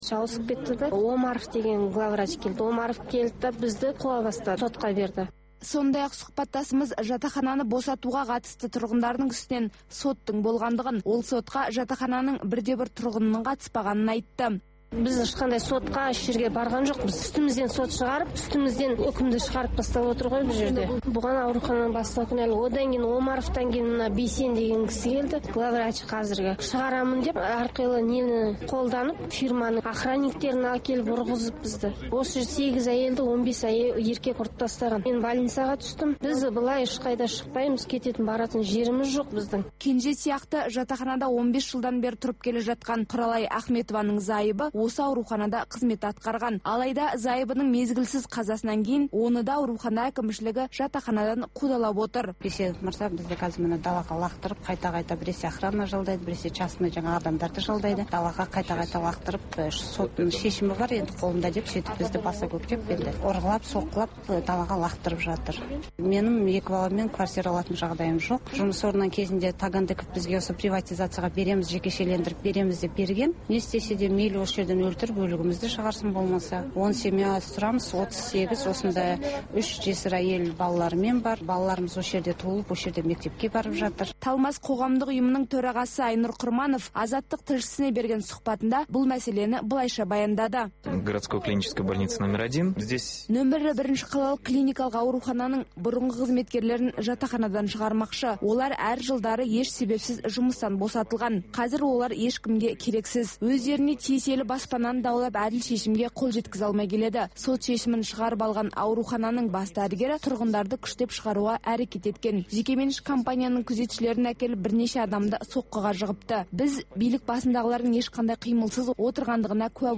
Бүгінгі күннің өзекті тақырыбына талқылаулар, пікірталас, оқиға ортасынан репортаж, сарапшылар талдауы мен қарапайым азаматтардың еркін пікірі, баспасөз шолуы, тыңдарман ойы.